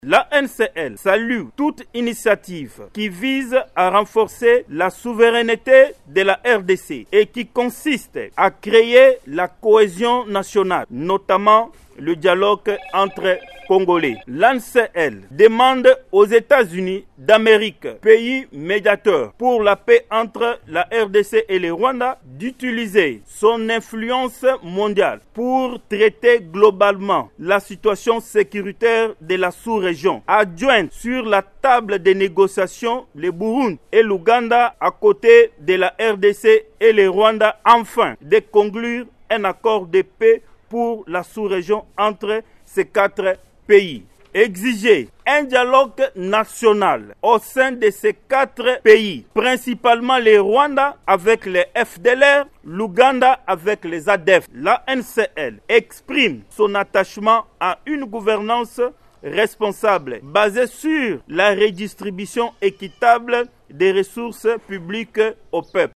Déclaration
Voici un extrait de la déclaration